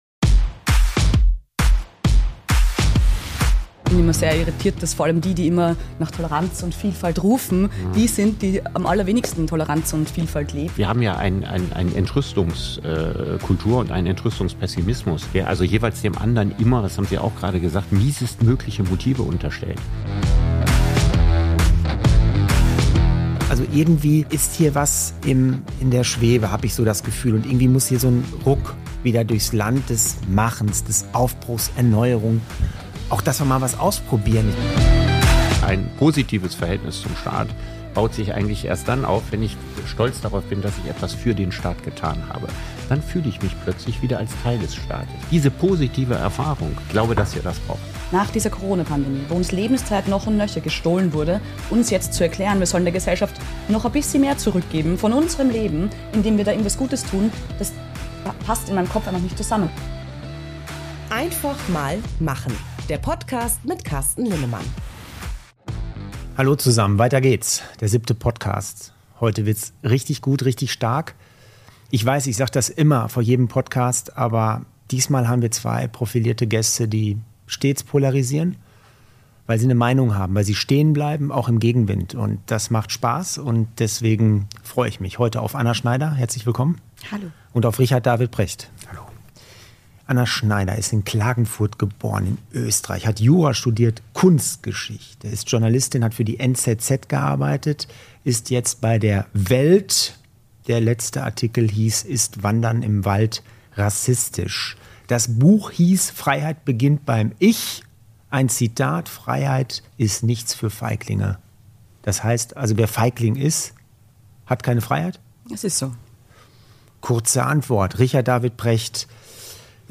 Diesmal mit dabei in Folge #7: WELT-Chefreporterin Anna Schneider und der Philosoph und Bestseller-Autor Richard David Precht. In der Runde mit Carsten Linnemann diskutieren die beiden Top-Gäste über die Polarisierung der Gesellschaft, über das Verhältnis von Bürger und Staat, die Balance von Pflicht und Freiheit und darüber, wie der Ruck aussehen sollte, der durch unser Land gehen muss.